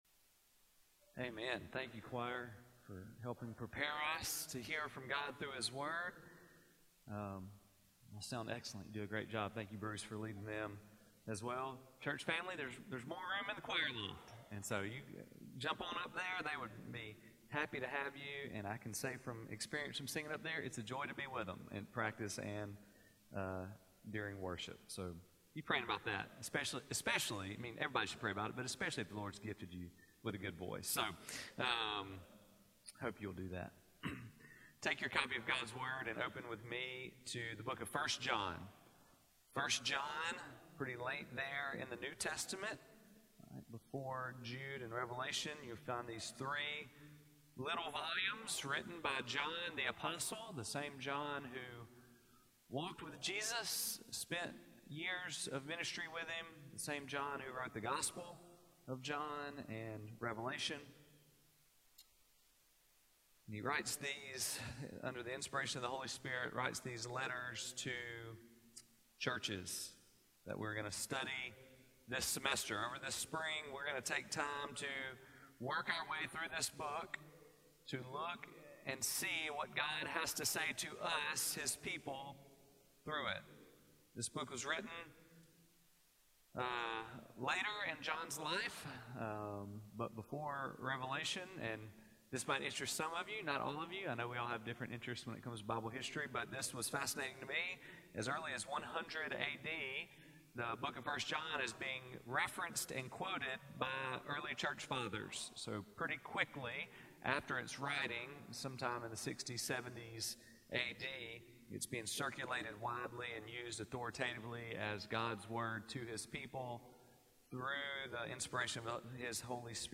Sermon Series: 1 John